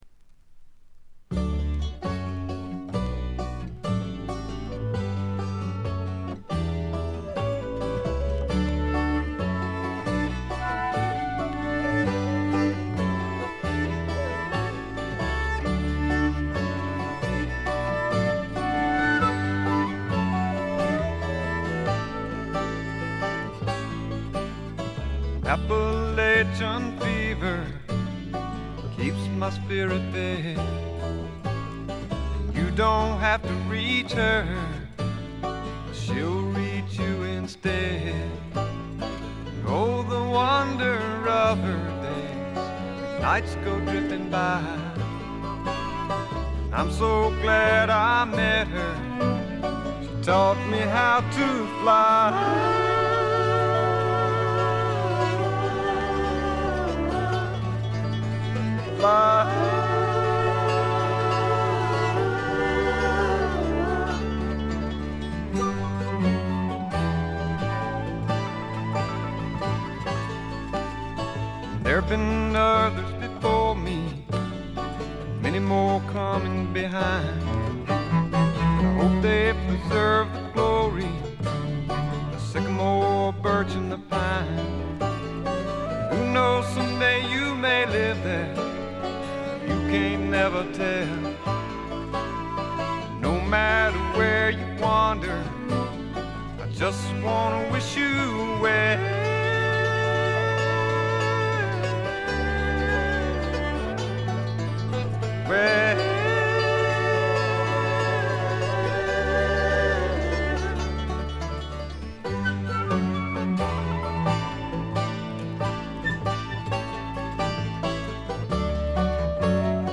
ところどころでチリプチ、プツ音少し。
フリーソウル的なポップ感覚が心地よいです。
試聴曲は現品からの取り込み音源です。